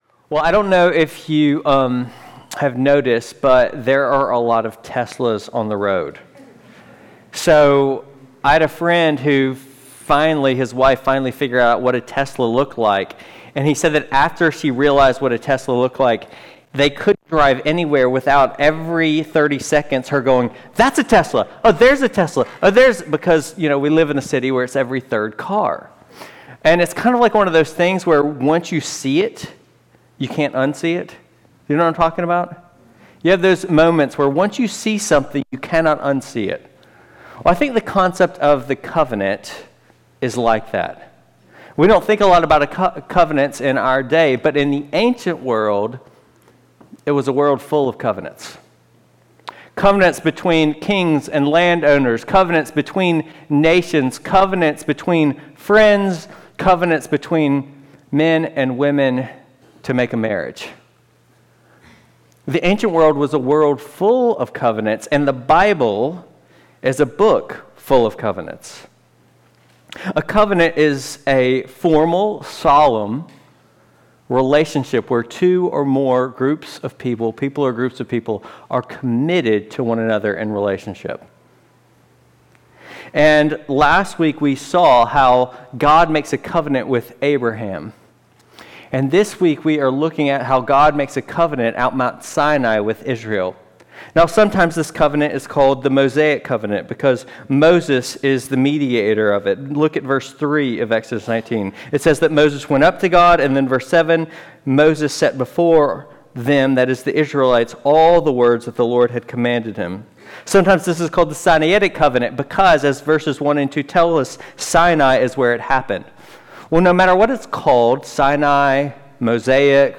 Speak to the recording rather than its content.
Passage: Exodus 19:1–8 Service Type: Sunday Worship